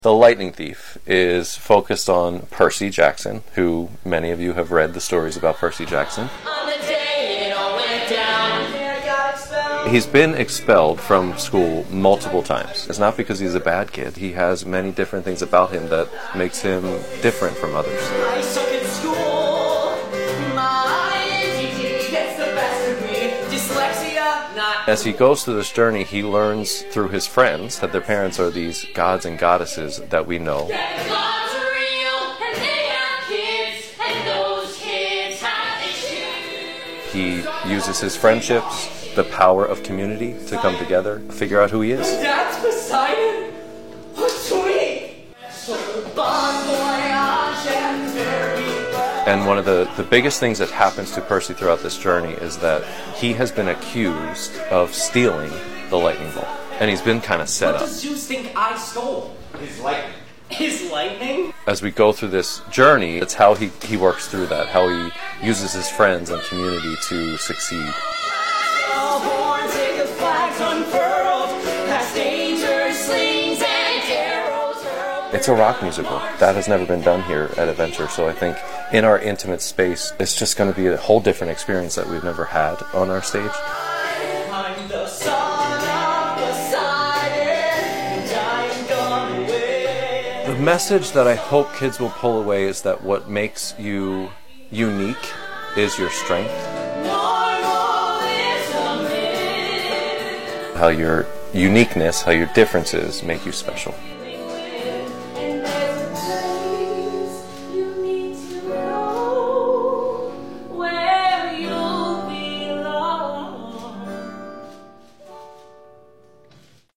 TYA Version in this exclusive interview!